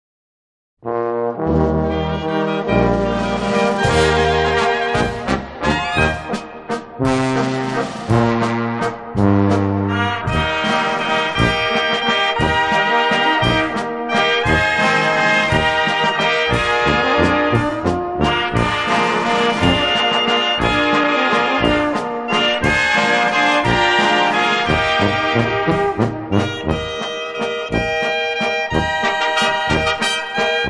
traditional Bavarian folk music